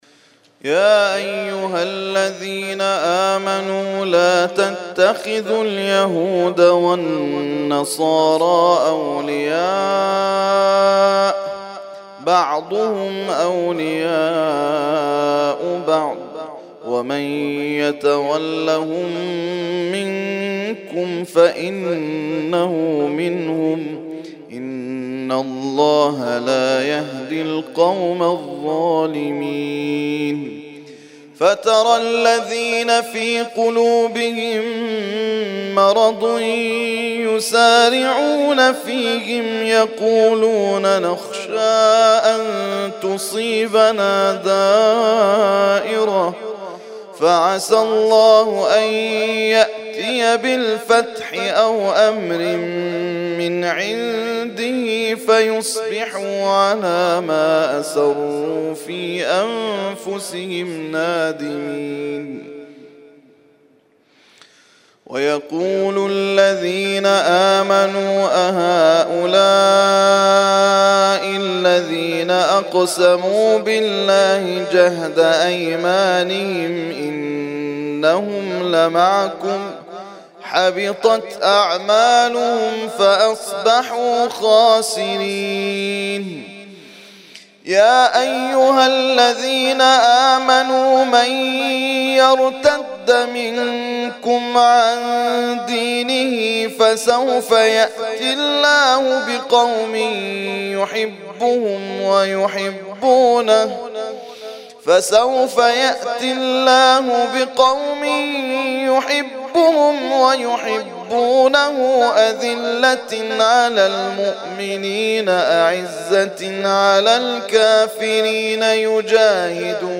ترتیل خوانی جزء ۶ قرآن کریم در سال ۱۳۹۳